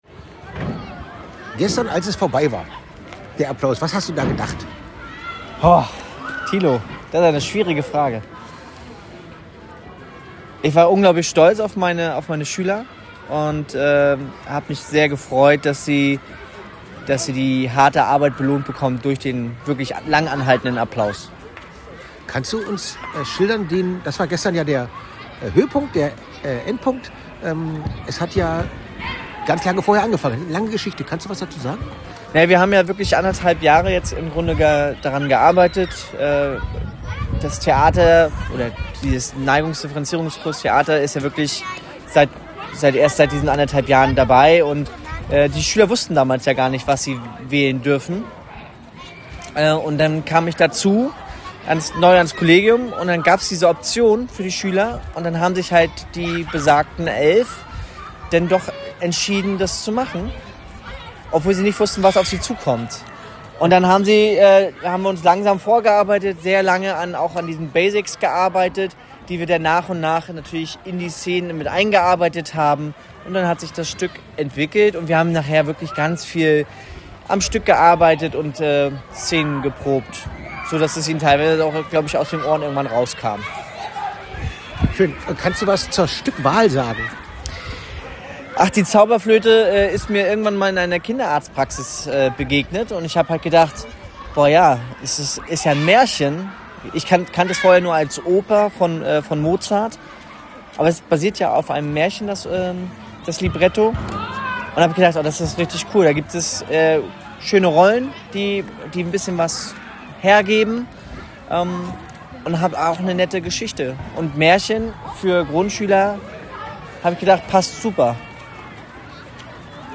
Kurzes Interview